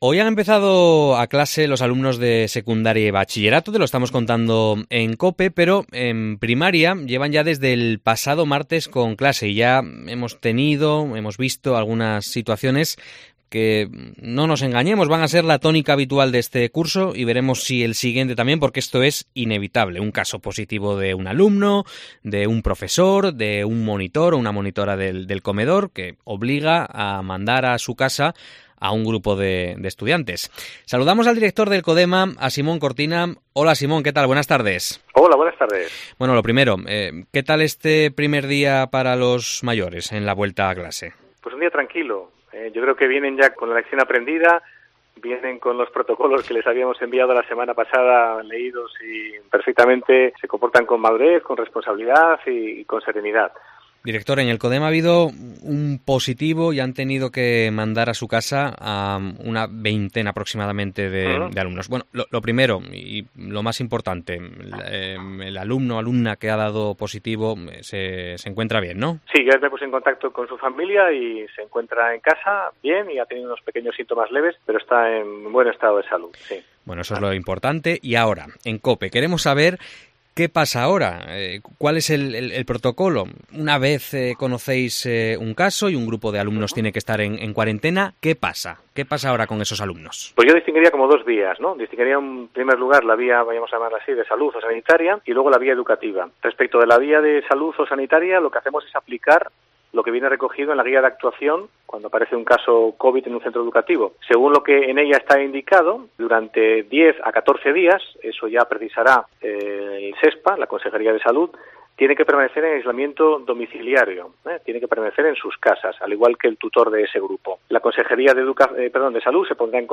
Respondemos en COPE